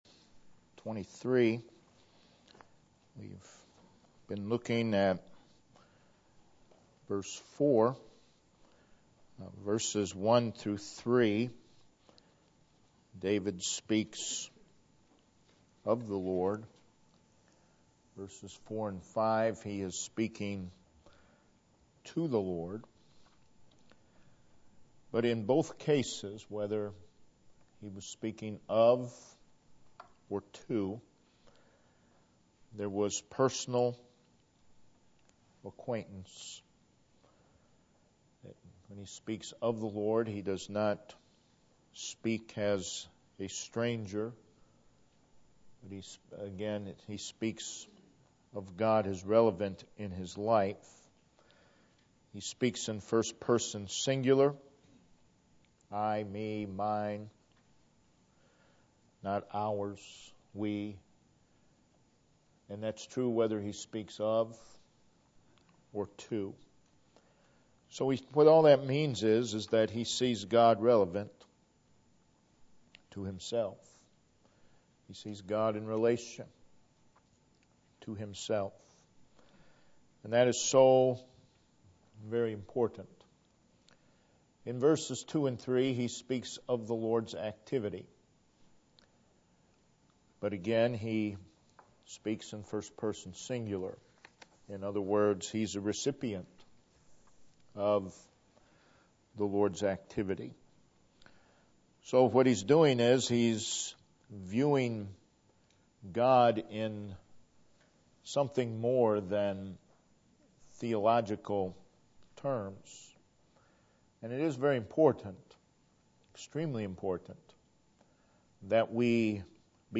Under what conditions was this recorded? December 2017 - Weekly Sunday Services This page presents the Sunday School lessons recorded at Home Missionary Baptist Church during our Sunday Services.